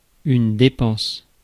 Ääntäminen
IPA : /ɪkˈspɛns/